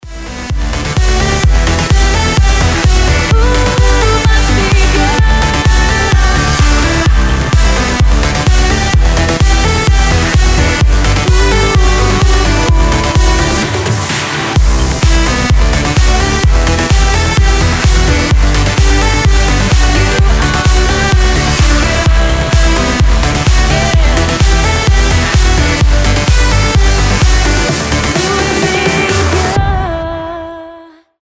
dance
EDM
красивый женский голос
progressive trance
Trance